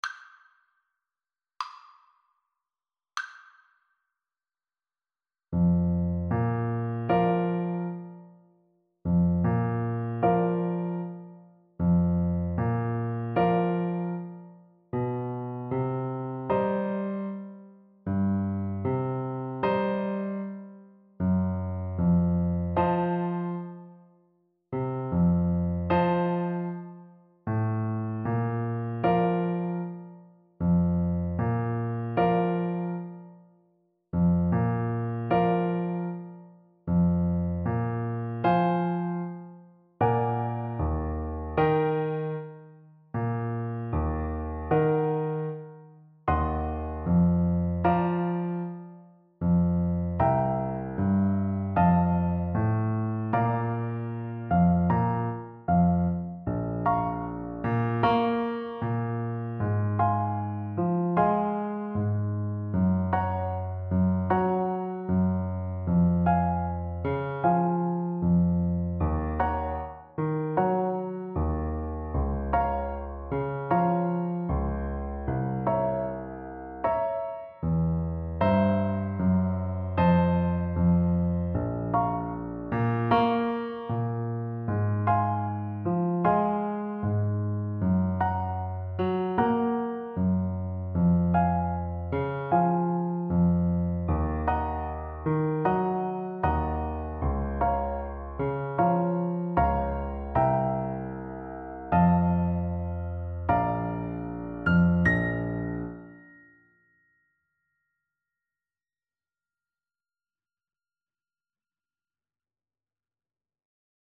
Orchestral Percussion version
Xylophone
tango song
2/4 (View more 2/4 Music)
World (View more World Percussion Music)